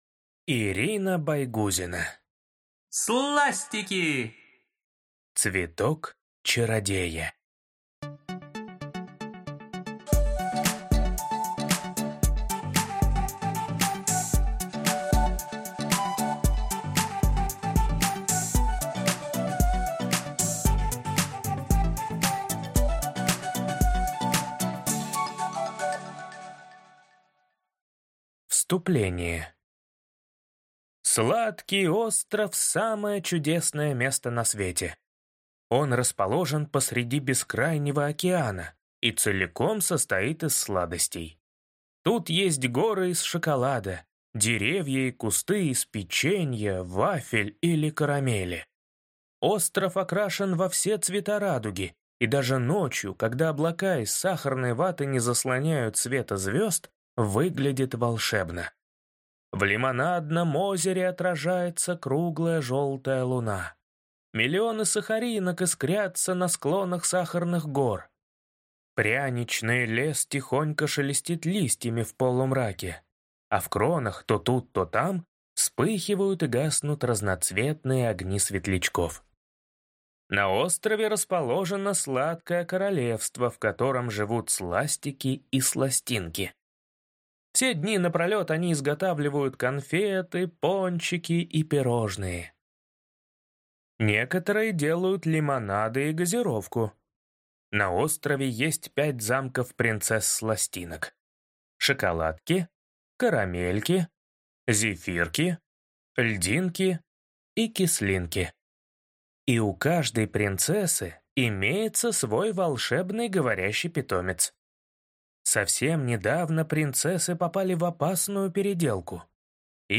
Аудиокнига Сластики. Цветок чародея | Библиотека аудиокниг